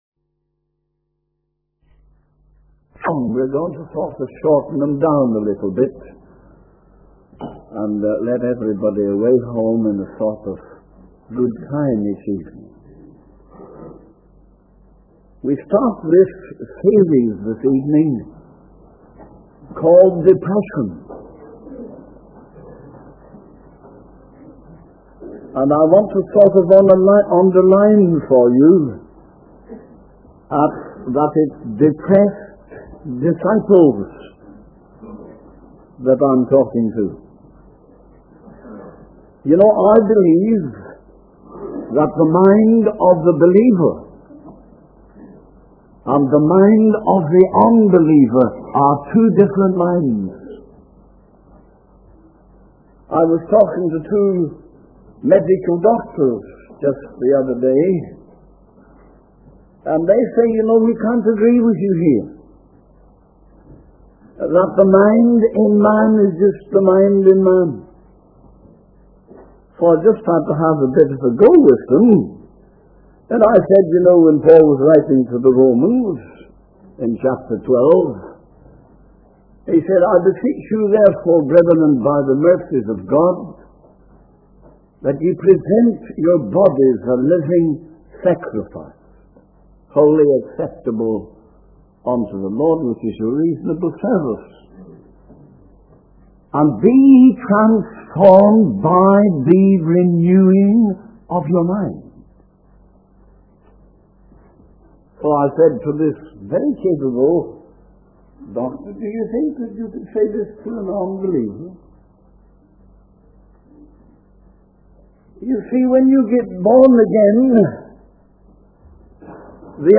In this sermon, the preacher emphasizes the power and sovereignty of the Lord over the enemy.